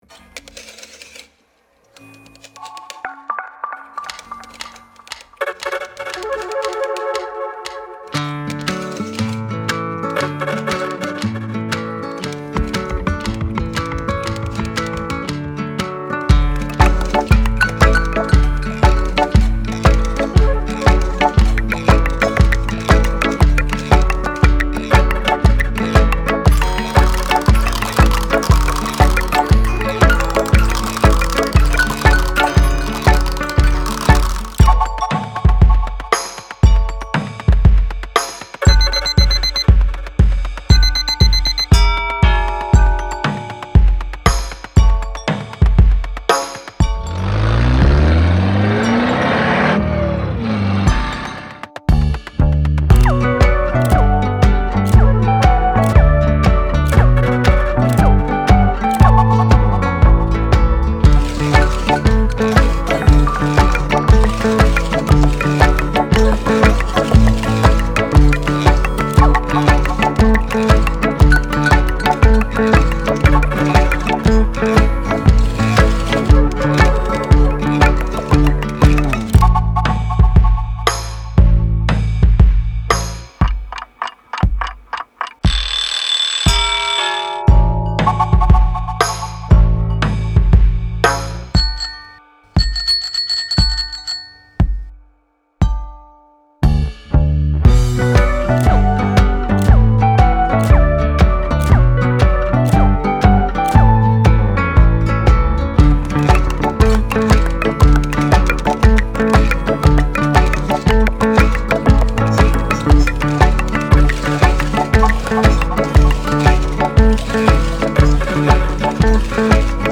Version instrumentale playback